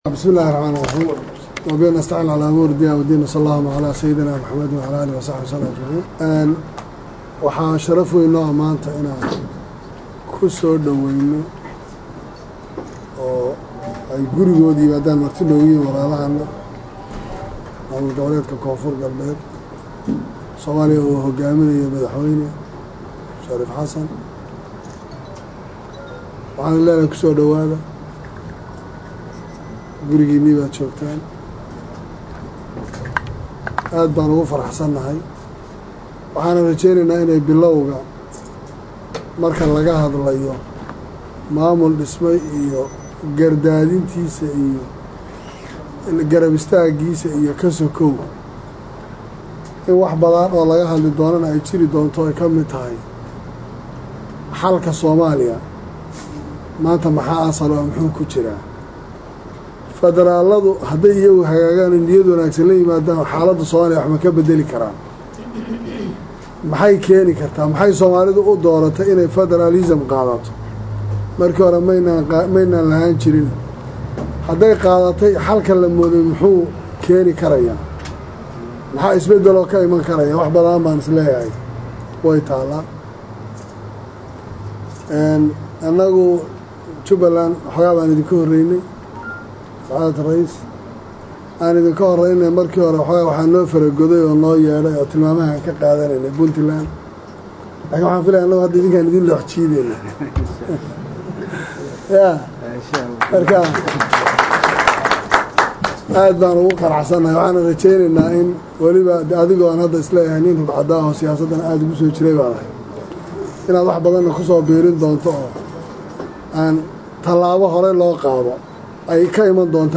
Kismaayo(INO)-Madaxweynaha maamulka Jubbaland Axmed Madoobe iyo kan Koonfur Galbeed Shariif Xasan oo maanta ku kulmey magaalada Kismaayo ayaa hadalo kooban siiyey saxaafadda xili halkaasi uu booqasho ku tagay madaxweynaha maamulka Koonfur Galbeed Shariif Xasan.
Halkan Ka Dhageyso Codka Hogaamiyaha Maamulka Jubba Axmed Madoobe.